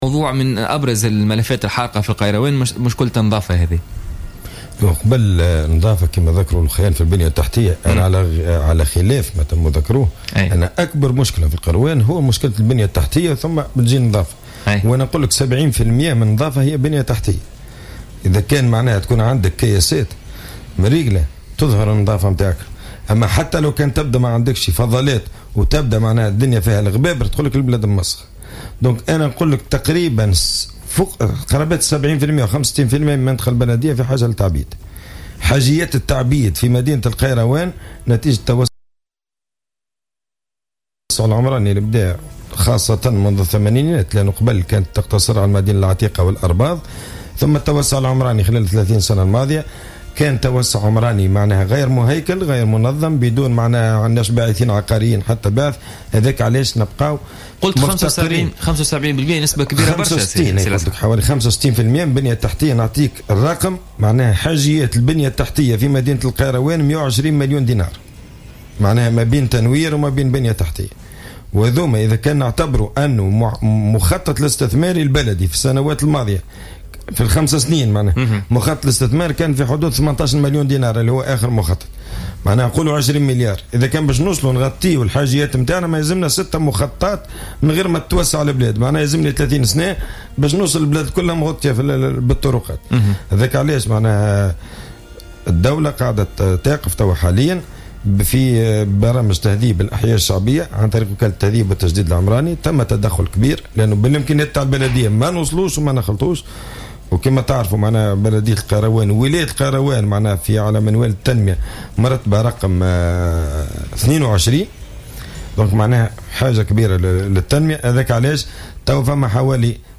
أكد رئيس النيابة الخصوصية بالقيروان،لسعد القضامي في مداخلة له اليوم الجمعة في برنامج "بوليتيكا" أن ضعف البنية التحتية من أبرز الإشكاليات التي تعاني منها الولاية،مشيرا إلى أن 65 بالمائة من المنطقة البلدية في حاجة للتعبيد.